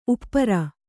♪ uppara